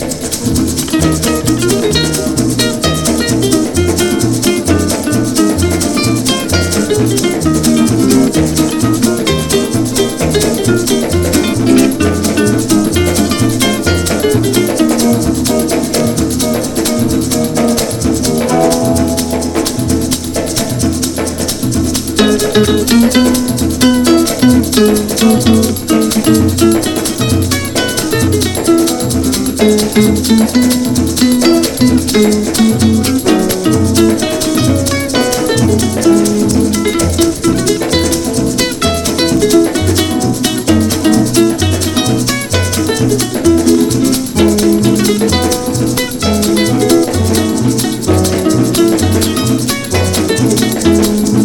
HAWAIIAN A.O.R.
FREE SOUL
滑らかなハーモニー、心地いいグルーヴにとことんヤラれる